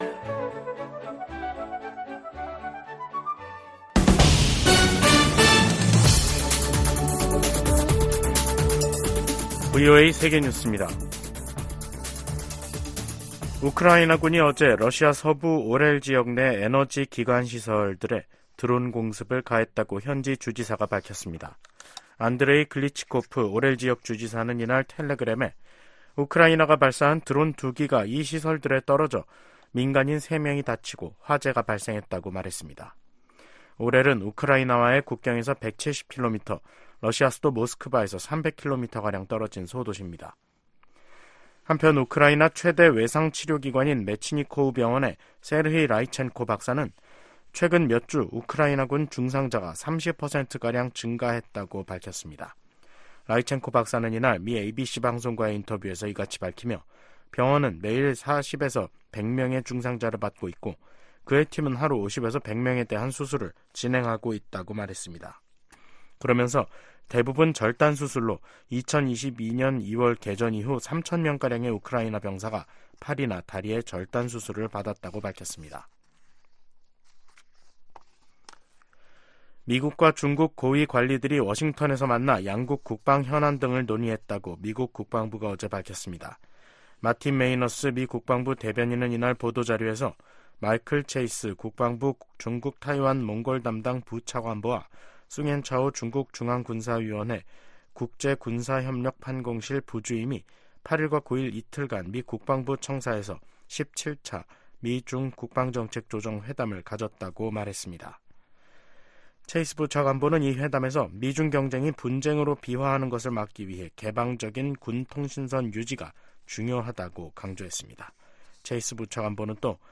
VOA 한국어 간판 뉴스 프로그램 '뉴스 투데이', 2024년 1월 10일 2부 방송입니다. 러시아가 또다시 우크라이나 공격에 북한산 탄도미사일을 사용했다고 백악관이 밝혔습니다. 미국과 한국,일본 등 49개국 외교장관이 공동성명을 내고 러시아의 북한 탄도미사일 사용을 강력 규탄했습니다. 김정은 북한 국무위원장은 한국을 주적으로 규정하면서 무력으로 자신들을 위협하면 초토화하겠다고 위협했습니다.